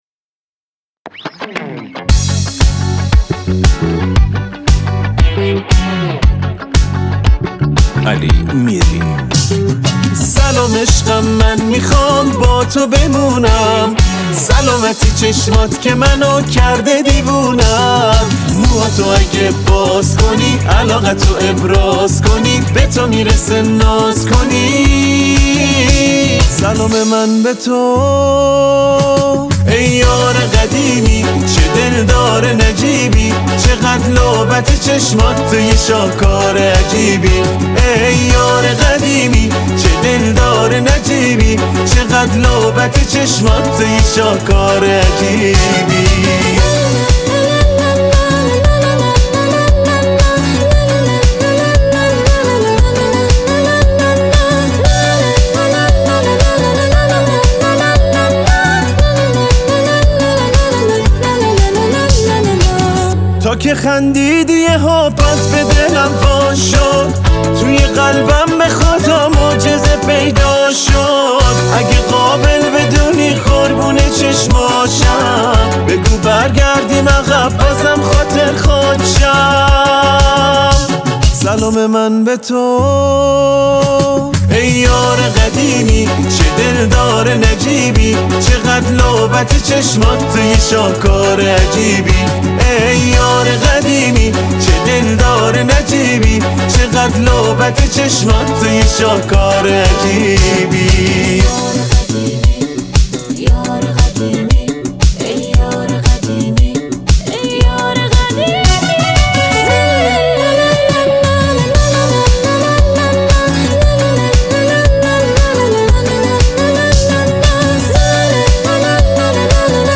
Iranian music